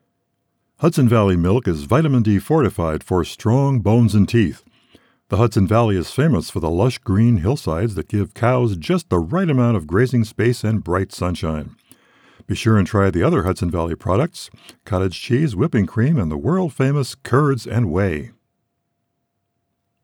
The second is another with the voicemeeter, at the proper distance.
With Voicemeeter at proper distance from mic:
I’m wondering what that “vacuum cleaner” sound in the background is.